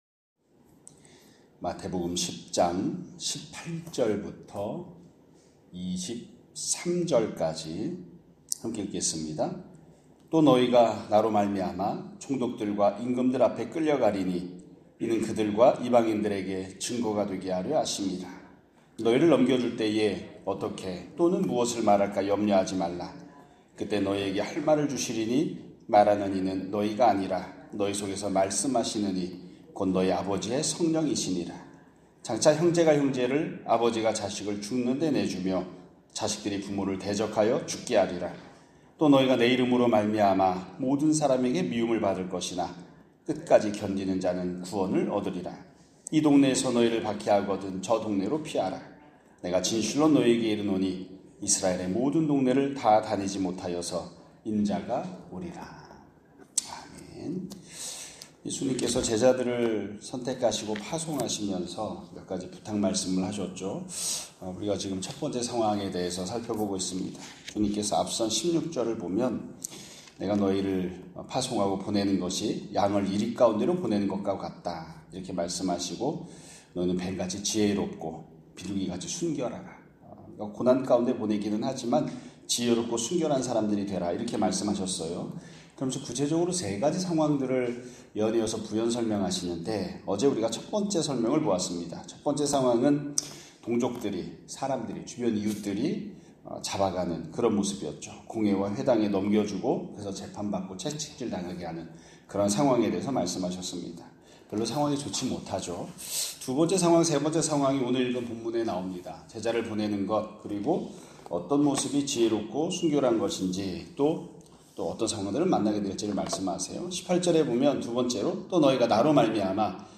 2025년 8월 8일 (금요일) <아침예배> 설교입니다.